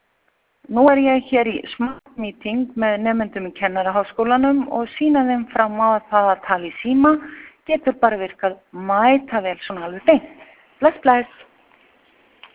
Skilaboð send á meðan á fjarfundi á SmartMeeting stóð með nemendum í framhaldsnámi KHÍ.